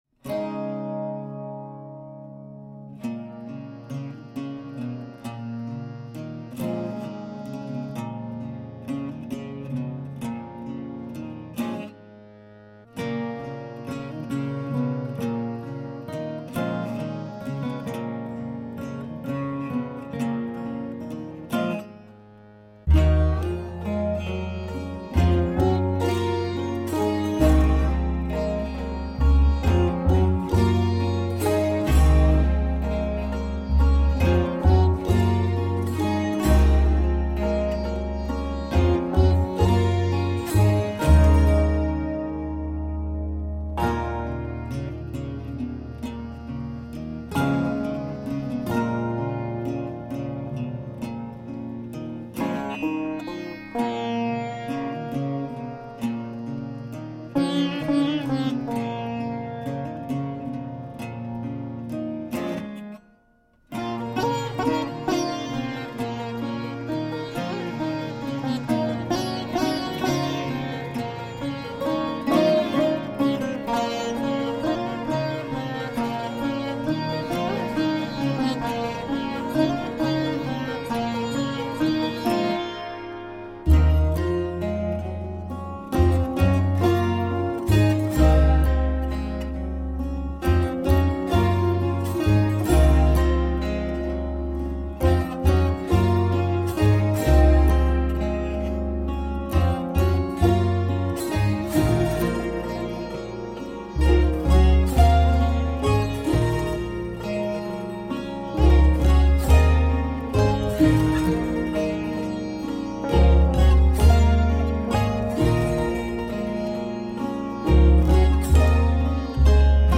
Music played by hand on instruments made of wood.
Tagged as: World, New Age